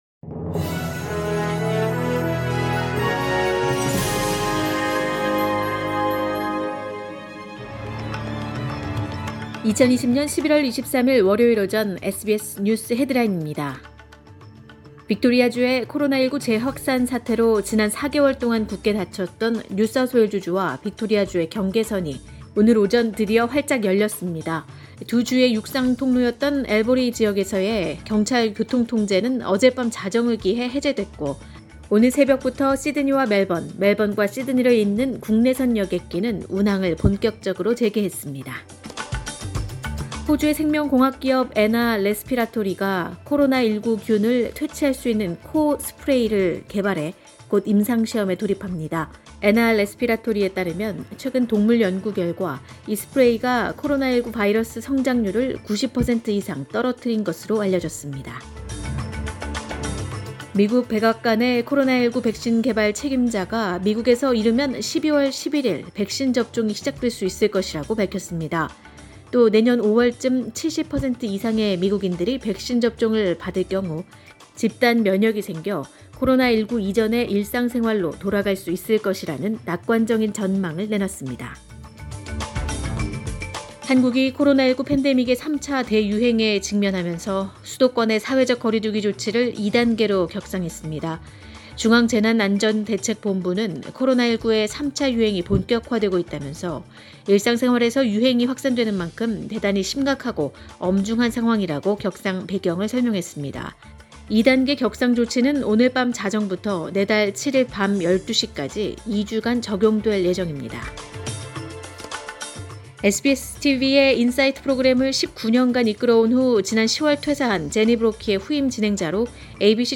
2020년 11월 23일 월요일 오전의 SBS 뉴스 헤드라인입니다.